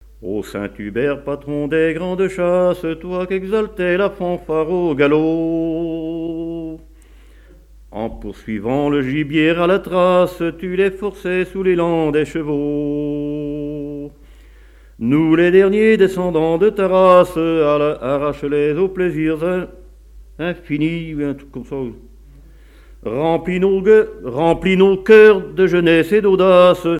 Trompe (Sonneurs de)
circonstance : vénerie
Pièce musicale inédite